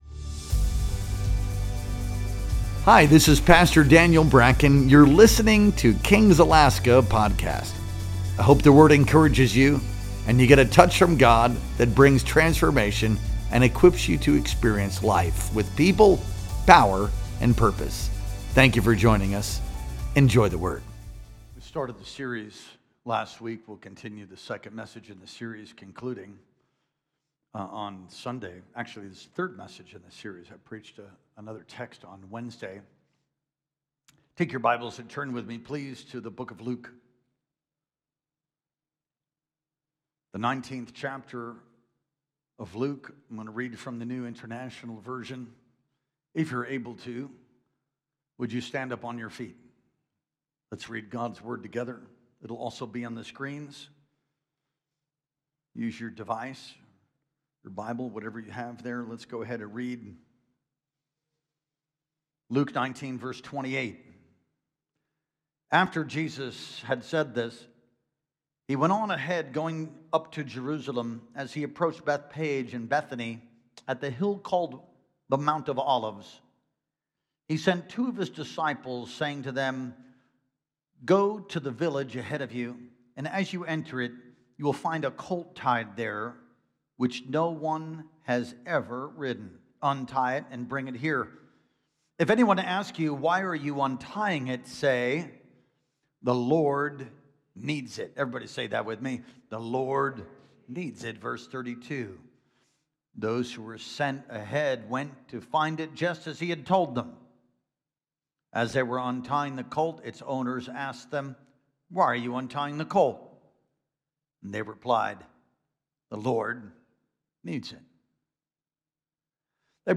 Our Sunday Worship Experience streamed live on April 13th, 2025.